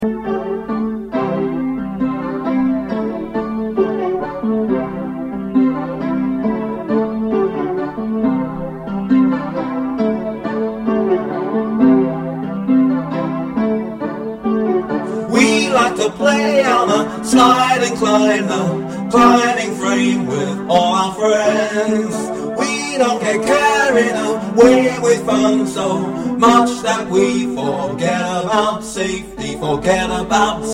Listen to the vocal track.